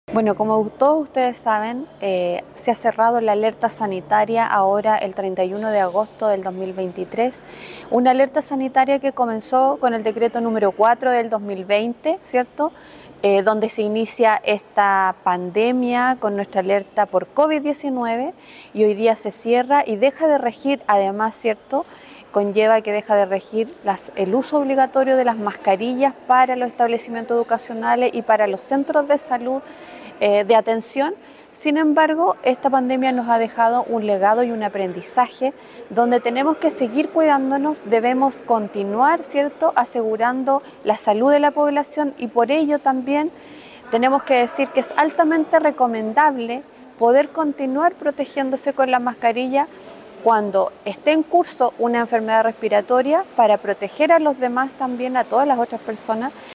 La Secretaria Regional Ministerial de Salud de Los Lagos, Karin Solís Hinojosa señaló que a pesar de que se terminó esta alerta, es recomendable continuar protegiéndose con la mascarilla ante un cuadro respiratorio.